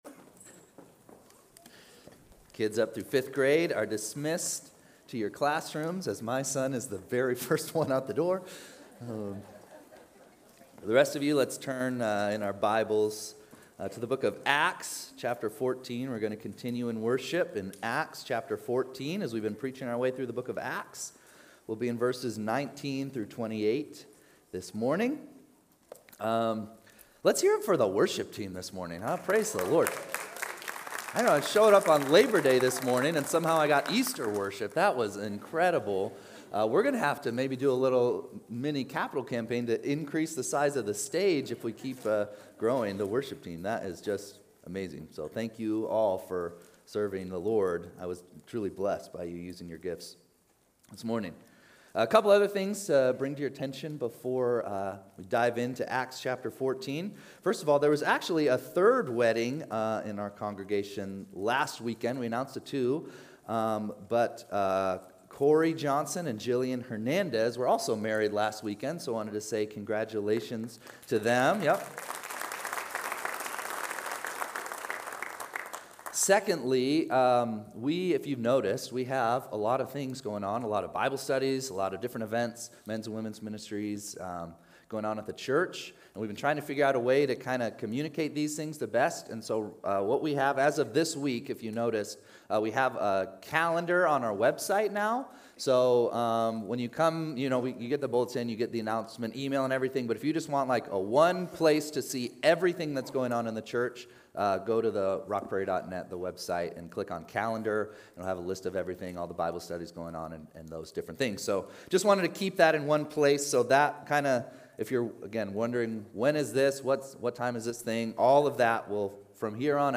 8-31-25-Sunday-Service.mp3